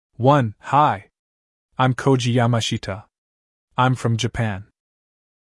Free AI Voice Generator and Text to Speech for IT Specialist